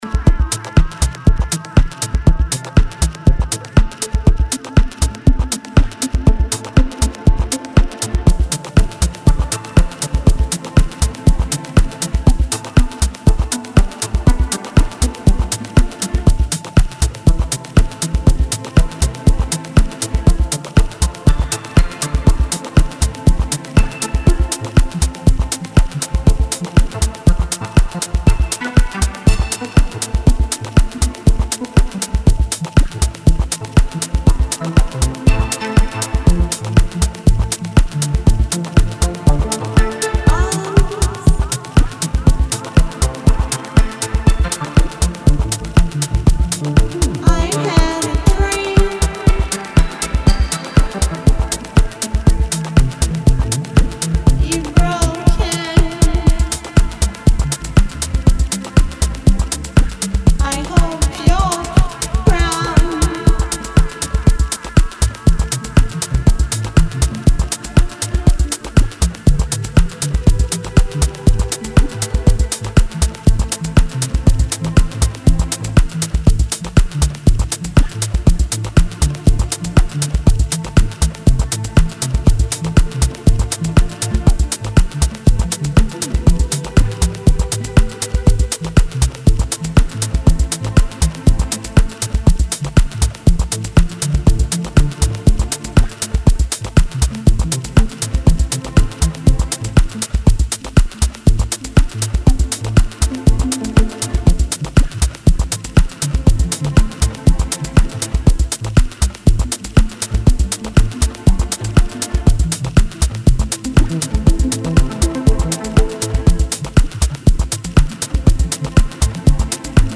smooth and Balearic atmosphere